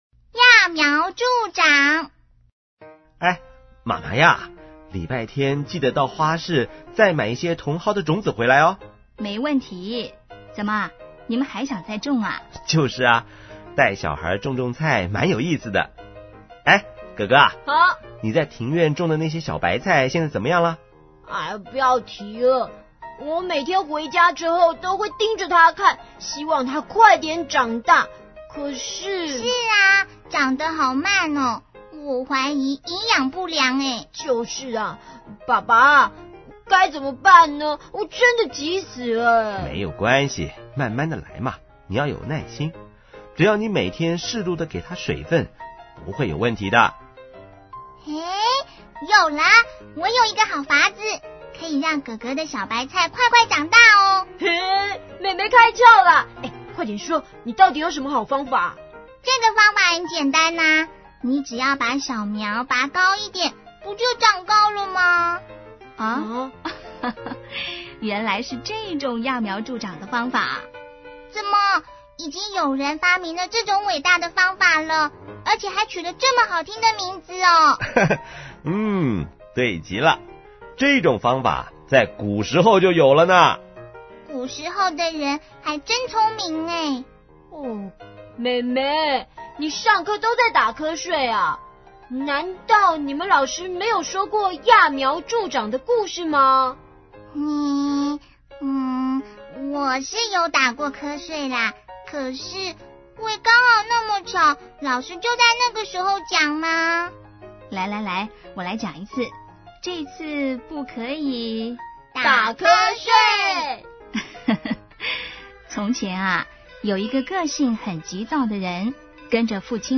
CD 每則成語均錄製成好聽生動的「廣播劇」，增加學習效果。